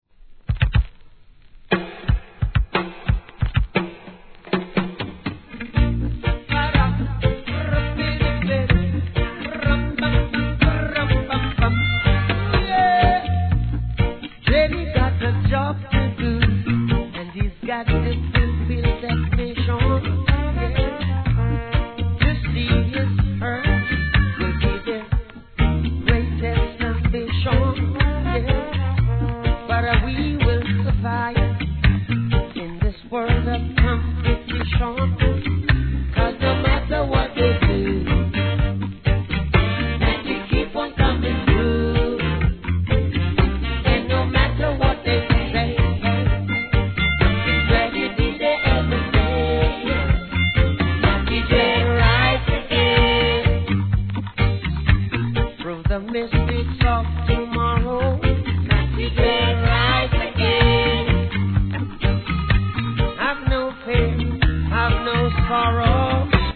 REGGAE
ハーモニカが絡む心地よいリズムで